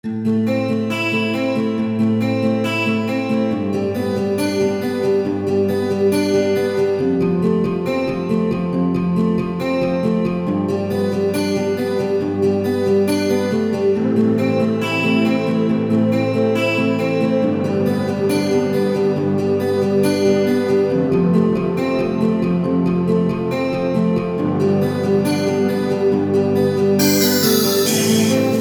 гитара
акустика
Зарубежная инструментальная музыка